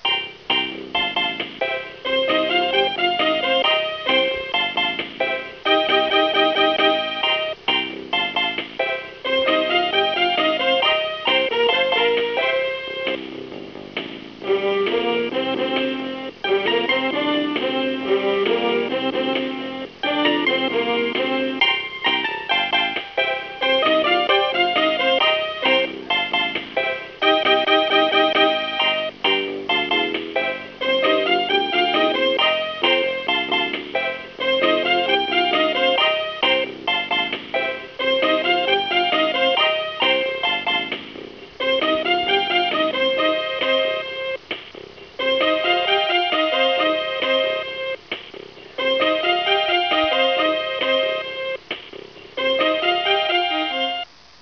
This is my favourite music in the classcial game